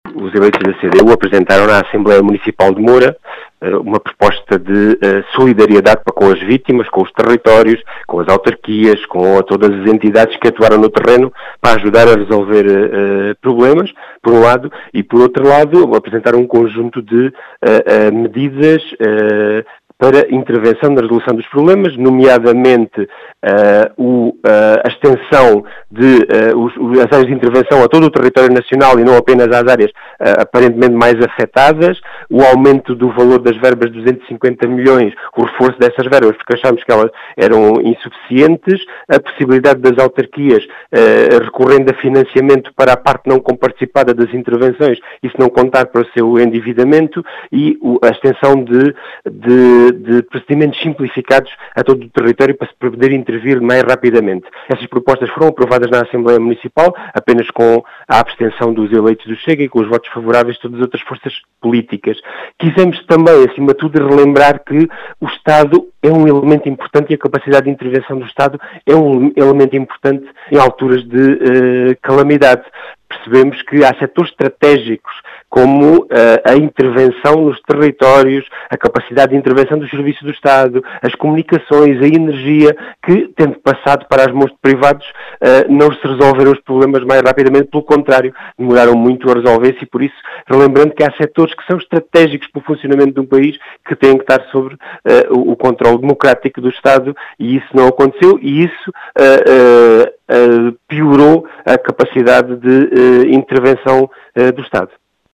As explicações são de João Ramos, eleito da CDU na Assembleia Municipal de Moura, que quer a extensão das medidas de apoio a todo o território continental.